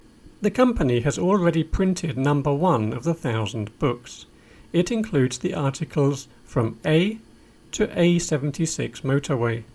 DICTATION 5